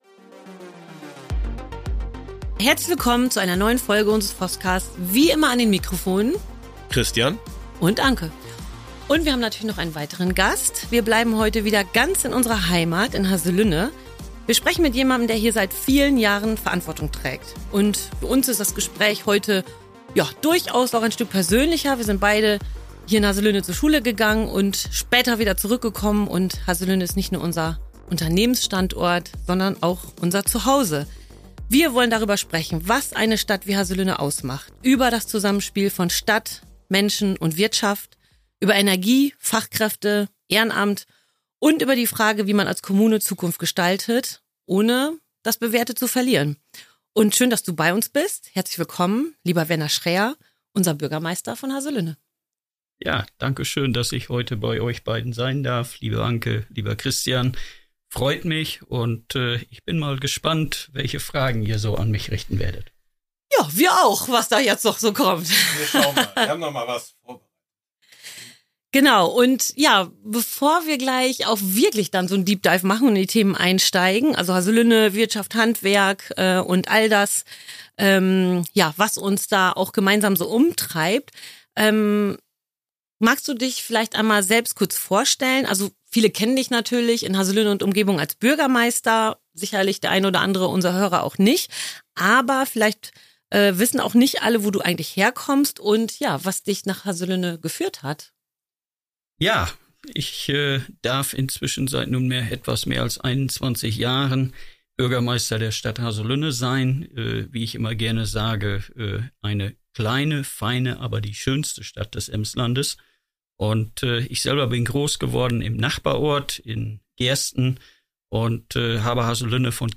In dieser Folge sprechen wir mit Werner Schräer, Bürgermeister der Stadt Haselünne, über Themen, die unsere Region bewegen – und direkt auch das Handwerk betreffen.
Werner Schräer teilt viele konkrete Einblicke und Zahlen zur aktuellen Lage in Haselünne – von der Energiewende auf kommunaler Ebene bis hin zu Fachkräftegewinnung, Bildung und Ehrenamt. Ein Gespräch über Heimat, Verantwortung und die Bedeutung einer Stadt, die Rahmenbedingungen schafft, damit Unternehmen und Menschen hier langfristig Zukunft finden.